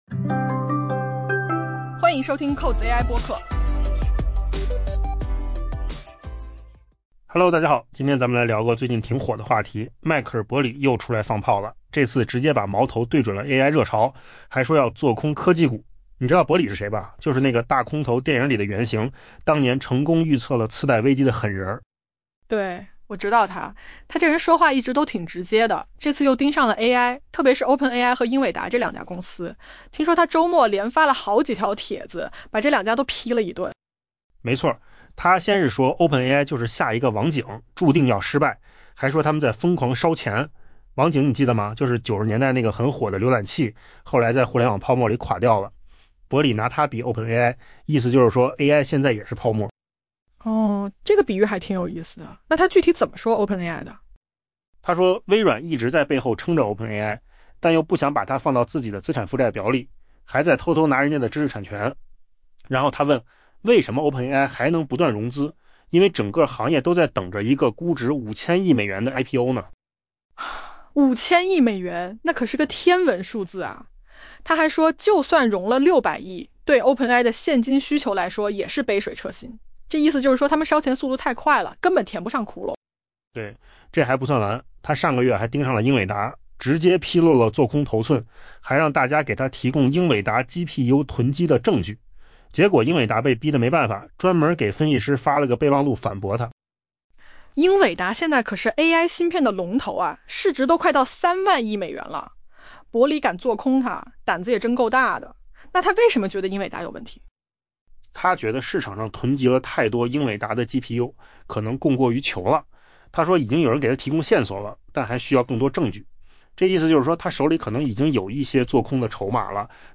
AI 播客：换个方式听新闻 下载 mp3 音频由扣子空间生成 迈克尔·伯里 （Michael Burry） 在上周末连发多条社交媒体动态，回击批评人士，并剑指股市当下最受追捧的科技热门交易。